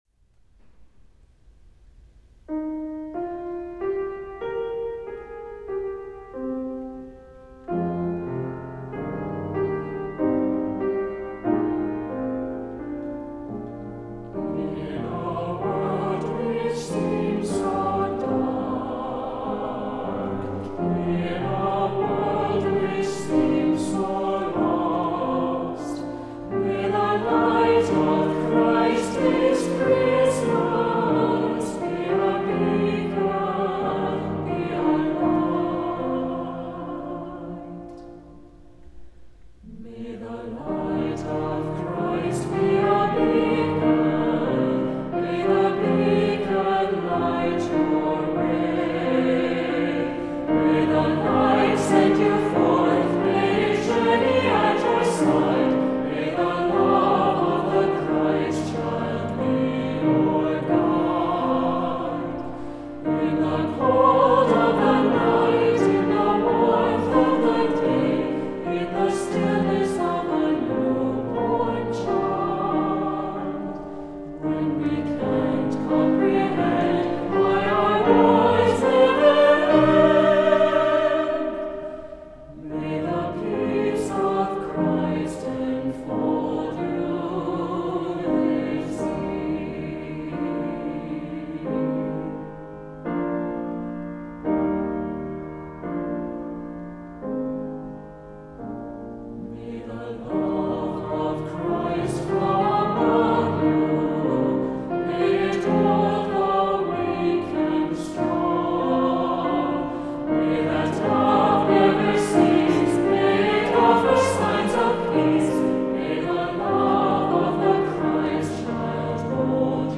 • Music Type: Choral
• Voicing: Unison
• Accompaniment: Keyboard
• Season: Christmas
in a refreshing, contemporary style